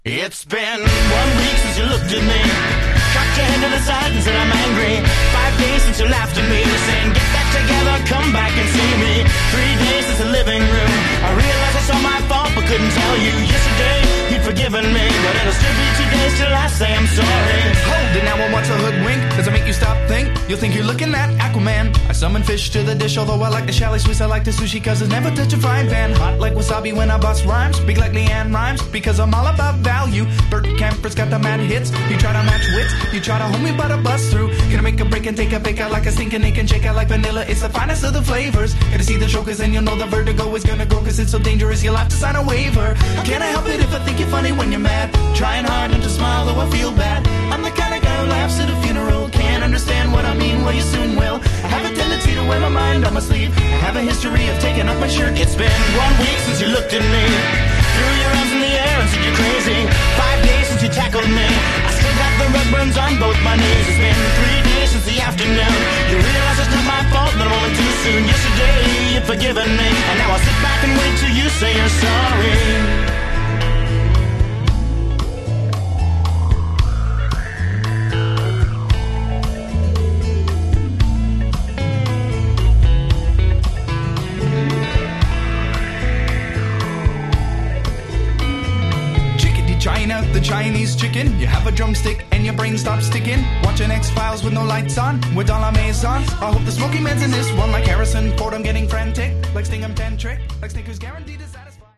Genre: Modern Rock